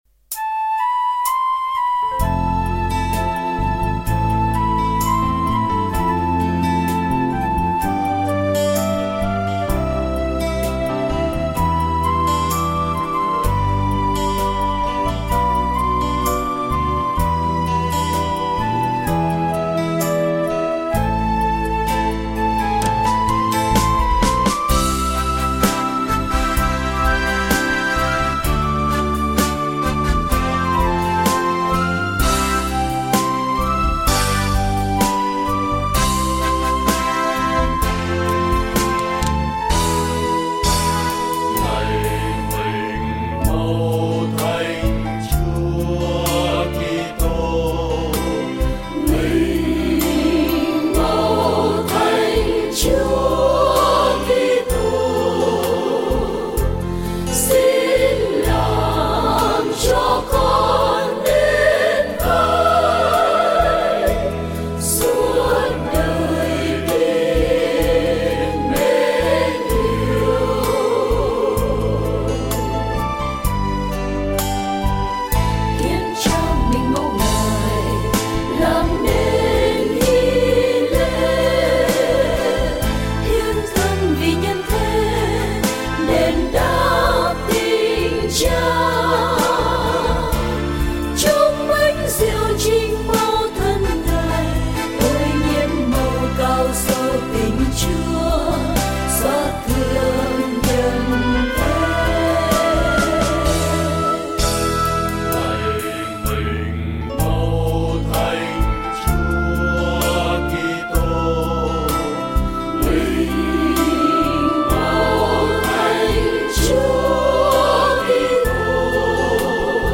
Thánh Ca về Thánh Thể